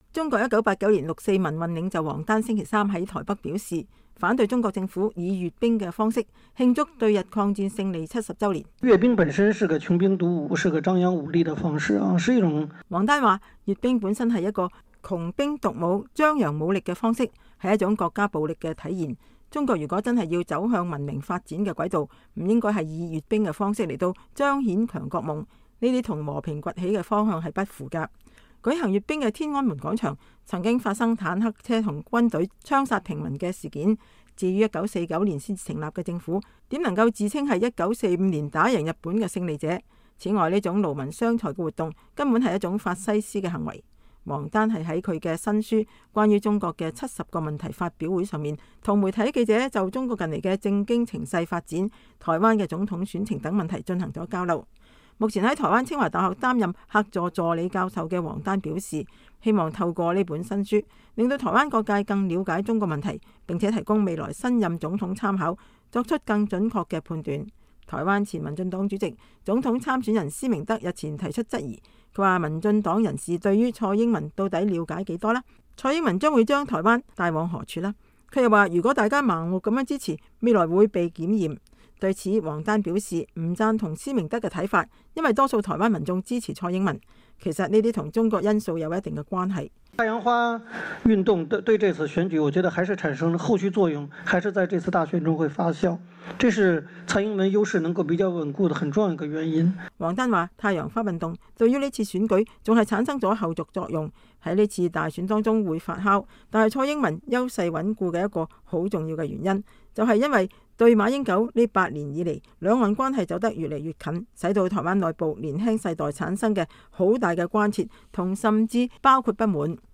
中國六四民運領袖王丹在新書發表會上講話